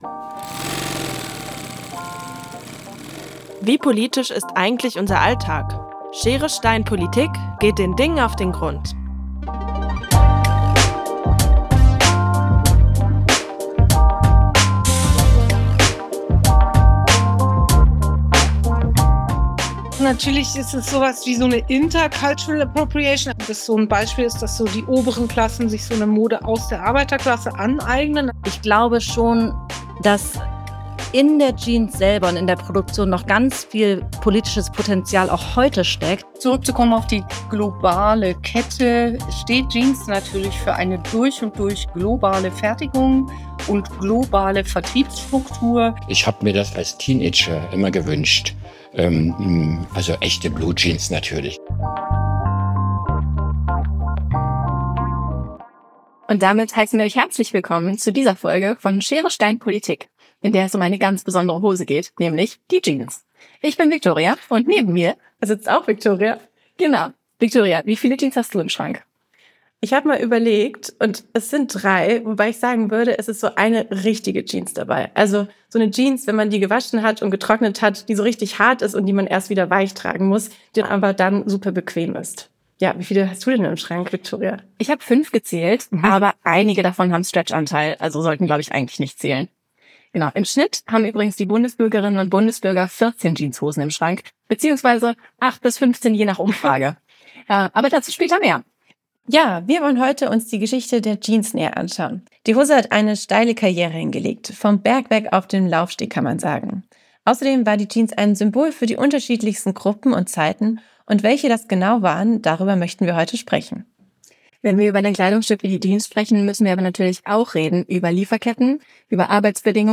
Schere, Stein, Politik – Die Jeans Ein Podcast der Volontärinnen und Volontäre der bpb